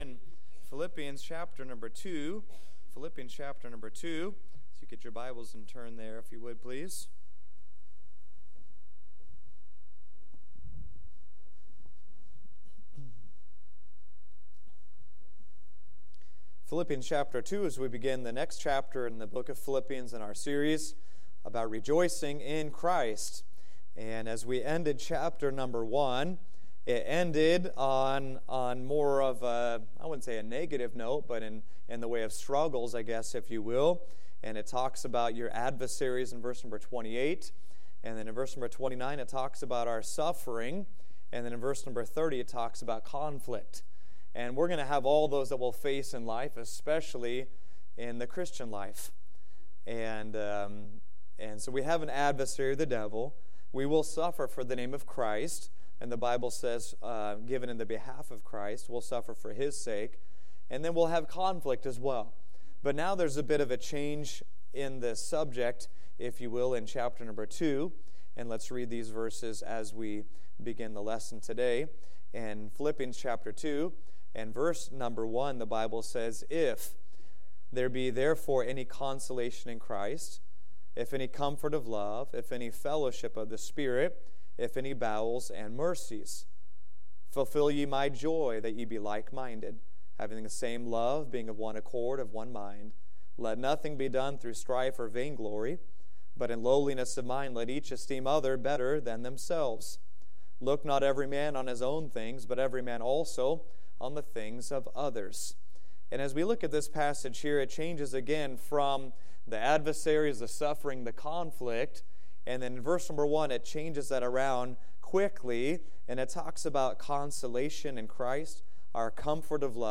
Finding Comfort in Christ | Sunday School – Shasta Baptist Church